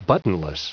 Prononciation du mot buttonless en anglais (fichier audio)
Prononciation du mot : buttonless